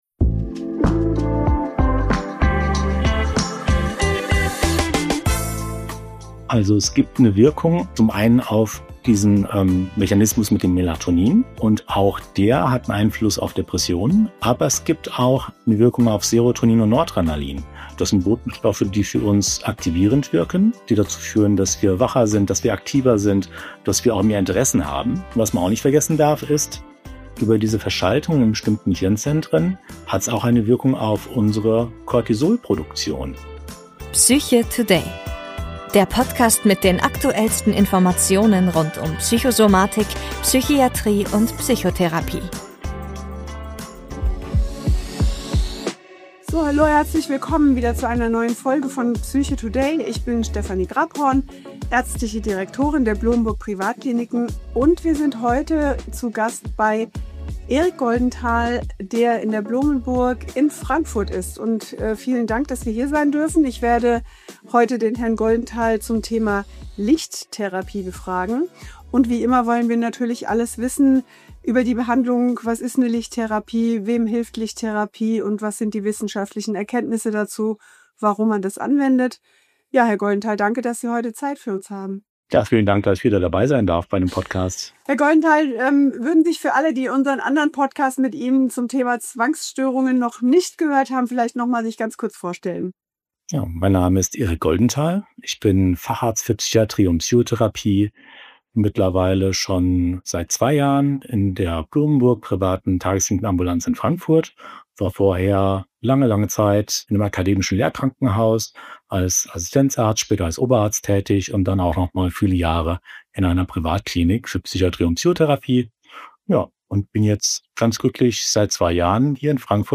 Im Interview erklärt er verständlich, wie Lichttherapie eingesetzt wird, welche Effekte sie auf Körper und Psyche haben kann und für wen sie besonders geeignet ist. Gemeinsam ordnen wir das Thema fachlich ein, sprechen über wissenschaftliche Hintergründe und klären, worauf man bei der Anwendung achten sollte.